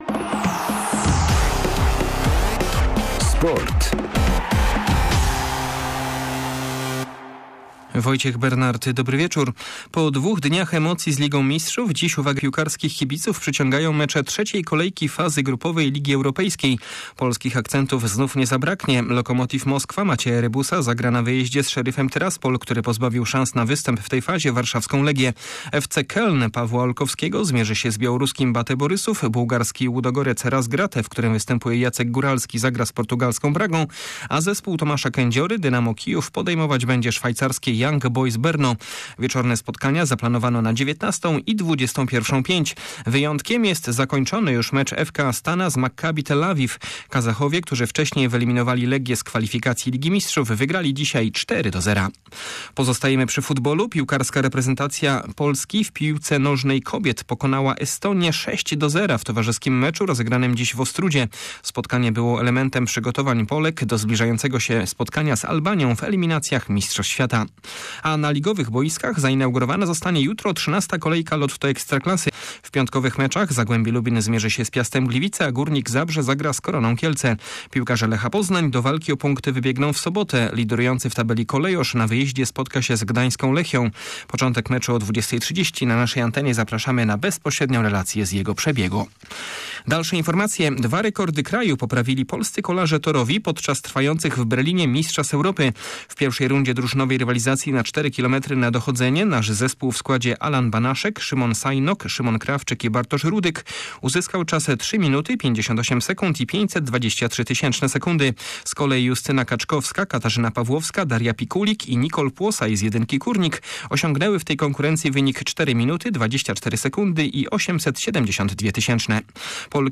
19.10 serwis sportowy godz. 19:05